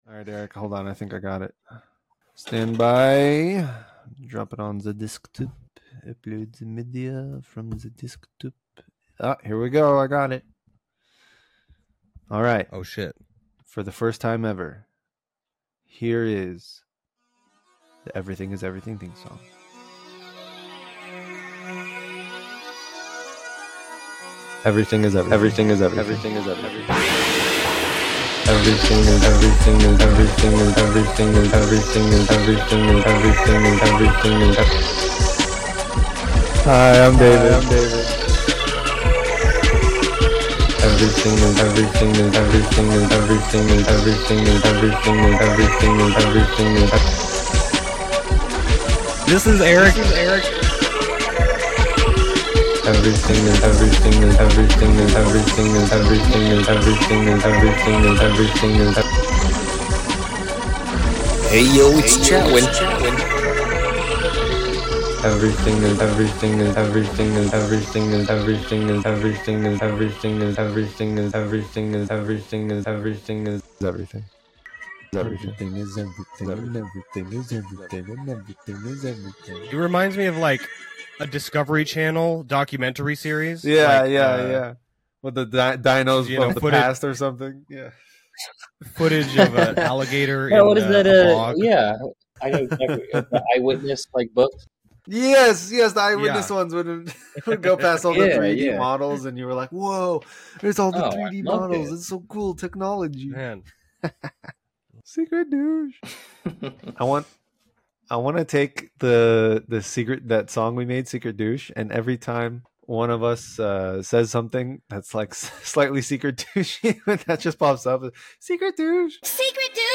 This is a show where 3 best friends discuss life, video games, movies, and everything in between.